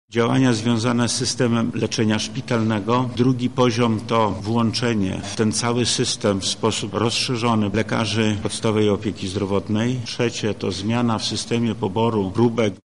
-mówi Wojewoda Lubelski Lech Sprawka.